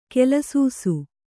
♪ kelasūsu